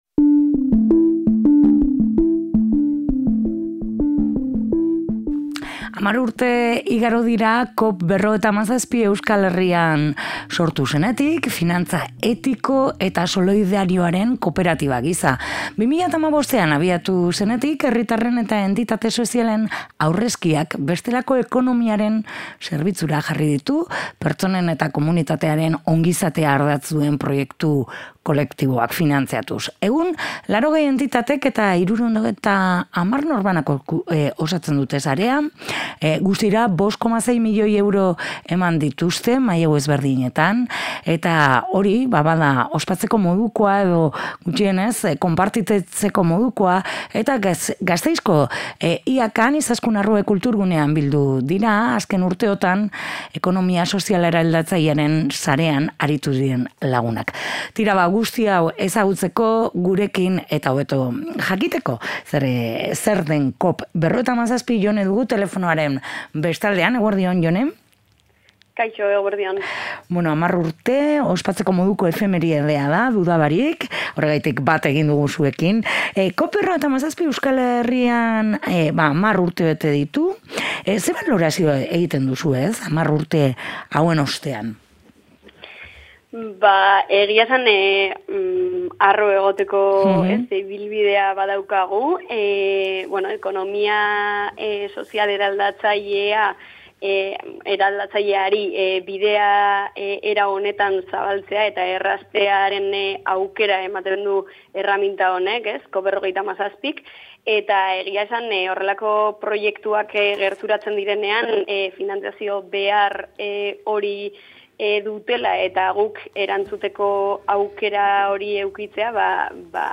10 urte ospatzeko moduko efemeride da, eta horregatik ere deitu egin diegu eta dagoneko prest dugu telefonoaren beste aldean